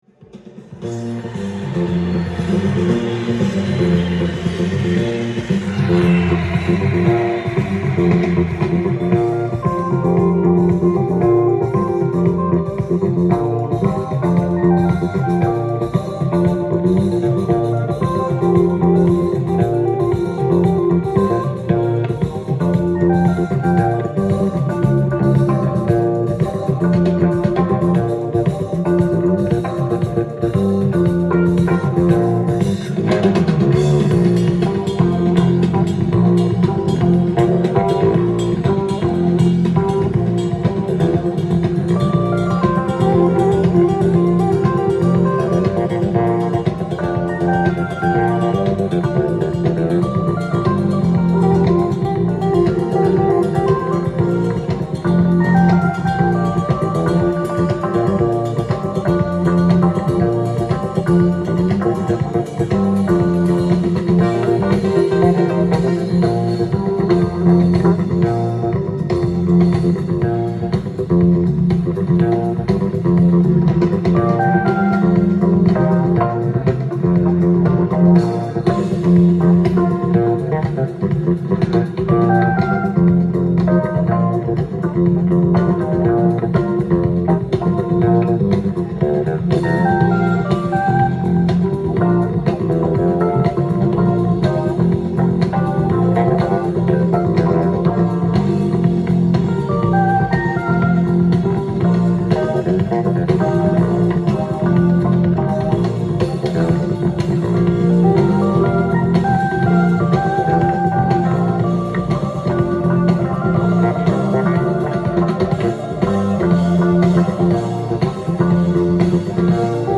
ジャンル：FUSION
店頭で録音した音源の為、多少の外部音や音質の悪さはございますが、サンプルとしてご視聴ください。
インサート有　2枚組　LIVE盤　ゲートフォールドJK